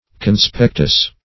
Conspectus \Con*spec"tus\ (k[o^]n*sp[e^]k"t[u^]s), n.
conspectus.mp3